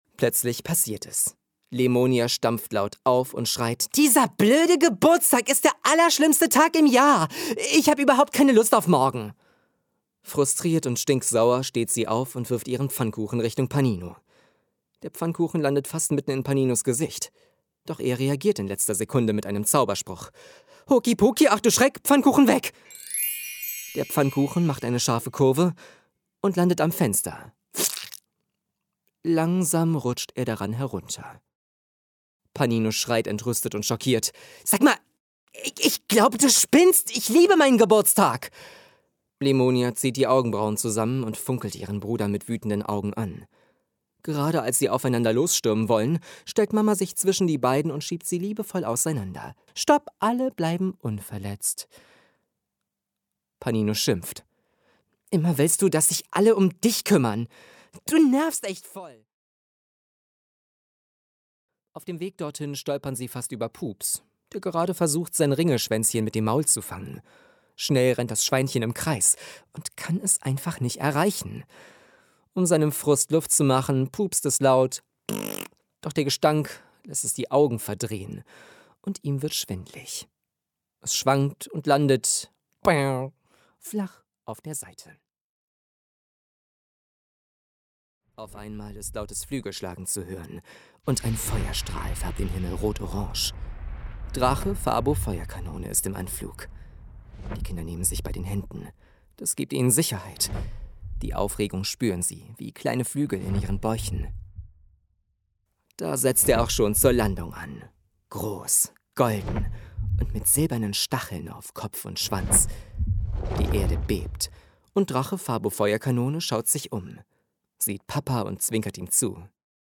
Im Hörbuch „Die magische Familie Fidibus“ erweckt er die verschiedenen Charaktere mit ihren ganz charakteristischen Ausdrucks- und Sprechweisen zum Leben und sorgt so dafür, dass die kleinen Hörenden von Kopf bis Fuß in die zauberhafte Welt eintauchen